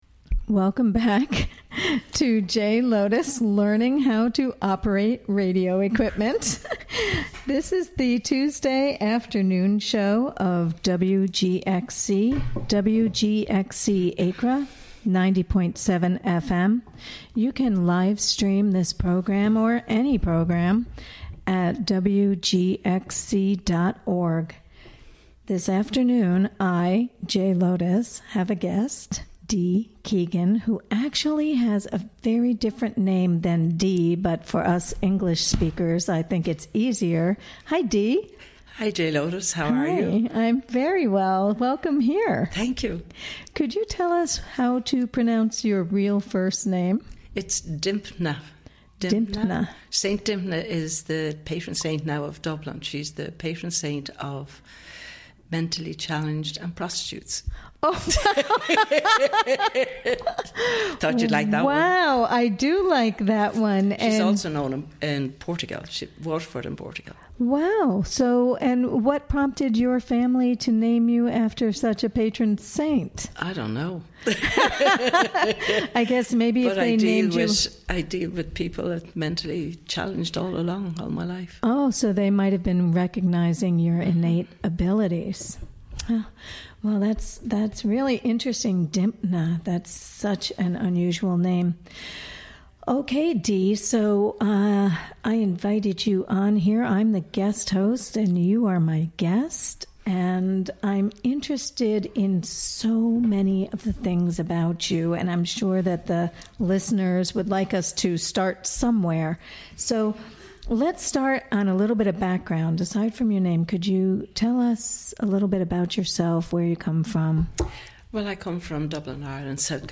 Interviewed
Recorded during the WGXC Afternoon Show of Tuesday, Oct. 3, 2017.